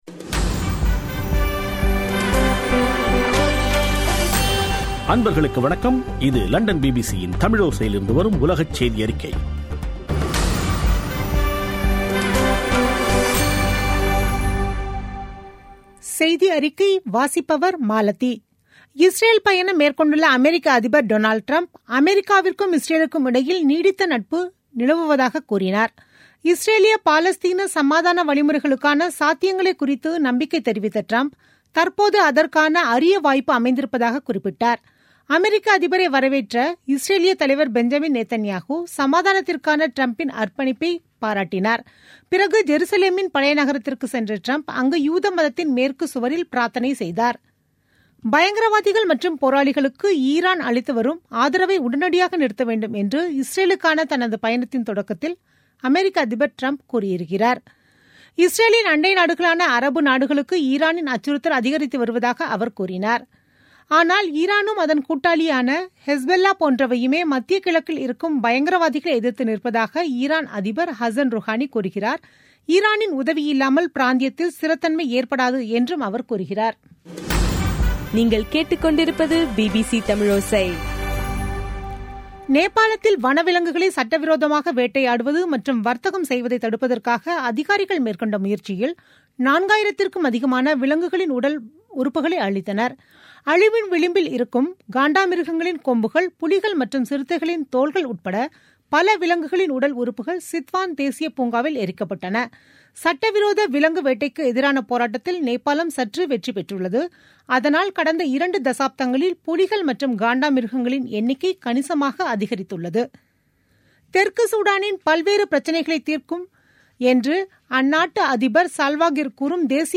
பிபிசி தமிழோசை செய்தியறிக்கை (22/05/2017)